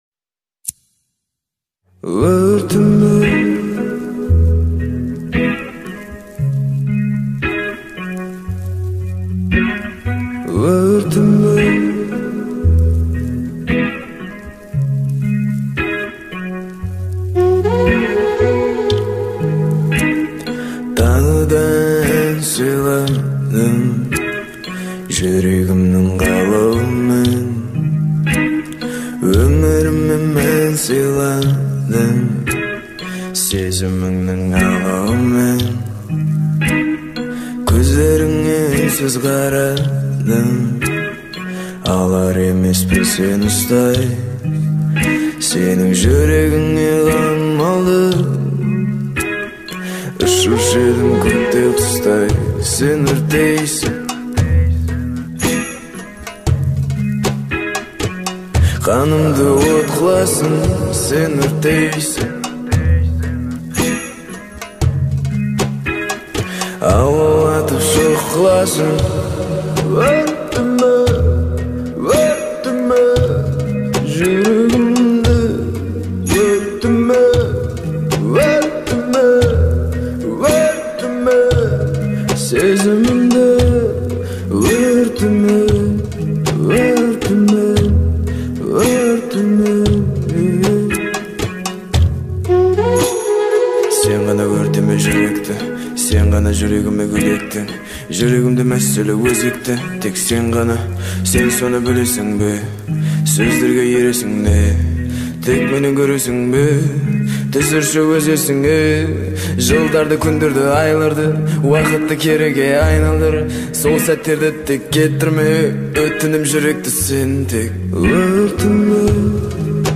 это яркая композиция в жанре поп-музыки